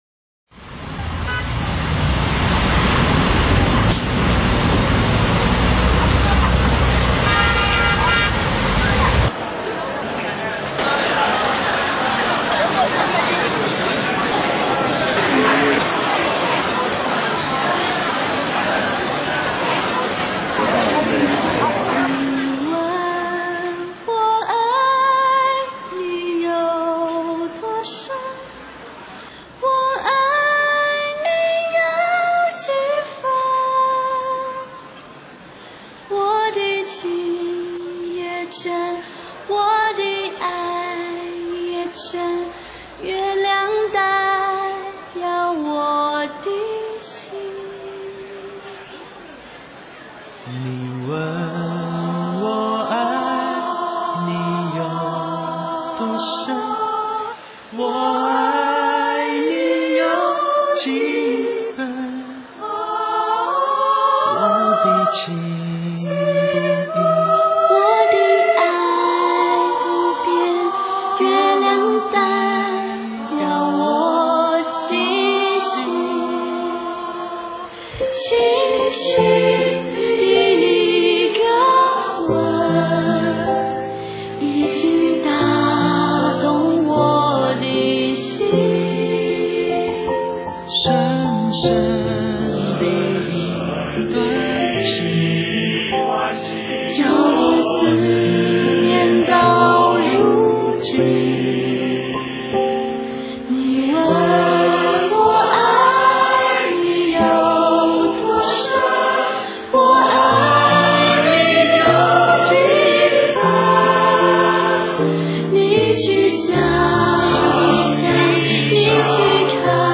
驚喜合唱 北京國貿 Flash Mob Chorus at CWTC Beijing(BD/MKV) 繼台北101的美食街快閃表演得到各界的好評後 驚喜合唱 至北京國貿 Flash Mob Chorus at CWTC Beijing 另一閃作品 一群喜好音樂的志願者在北京國貿的美食街快閃表演了美麗動人的歌曲，包括 "月亮代表我的心", "彎彎的月亮", "甜蜜蜜", "讓我們蕩起雙槳", "茉莉花", "站在高崗上" 以及 "高山青"。